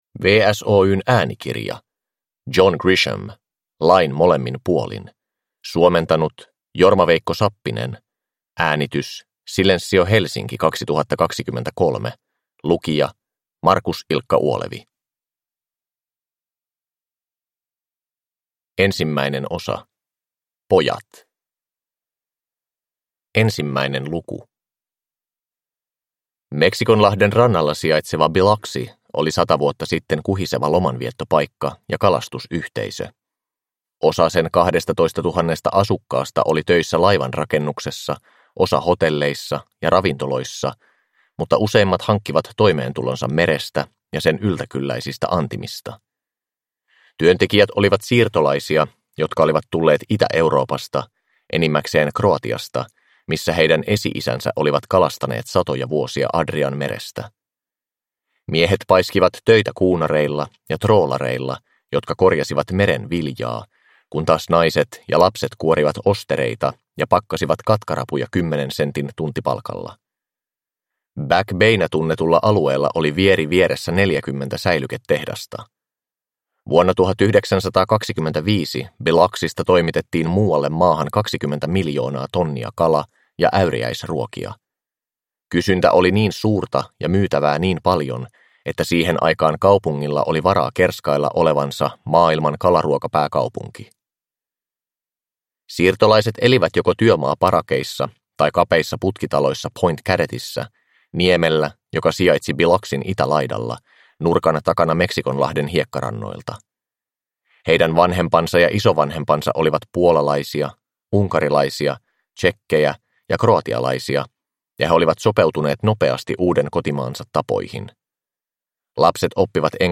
Lain molemmin puolin – Ljudbok – Laddas ner